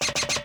tbd-station-14/Resources/Audio/Effects/Footsteps/clownspiderstep2.ogg at 0bbe335a3aec216e55e901b9d043de8b0d0c4db1
Adjust walking sounds
clownspiderstep2.ogg